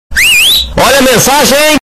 Descrição: Baixar o toque de notificação olha a mensagem estourado para WhatsApp, ZapZap nos celulares Android, Samsung, Vivo, Xiaomi, Motorola, realme e iPhone. O áudio fiu fiu olha a mensagem estourado em mp3 é também um som de memes engraçados que muita gente gosta.
toque-olha-a-mensagem-estourado-pt-www_tiengdong_com.mp3